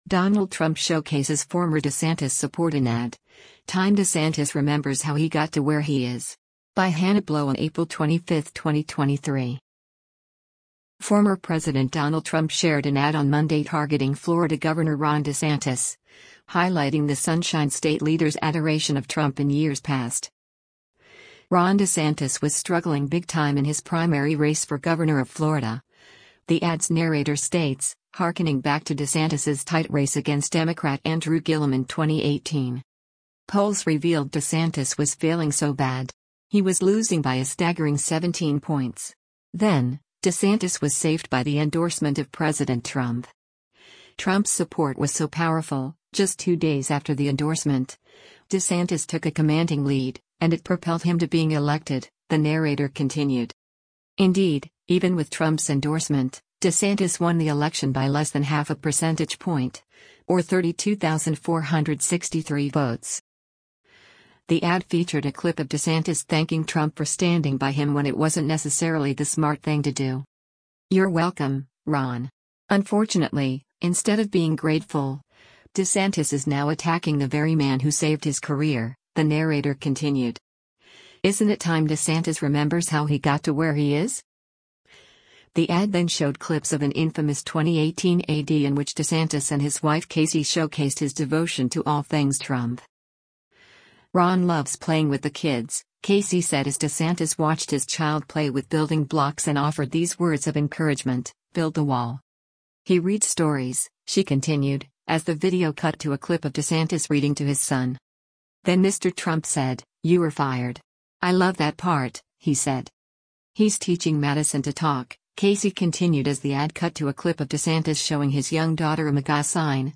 The ad featured a clip of DeSantis thanking Trump for standing by him “when it wasn’t necessarily the smart thing to do.”